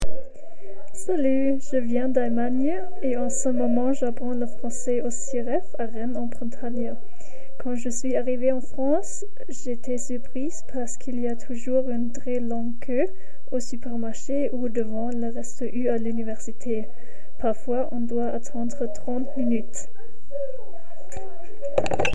Cabine de témoignages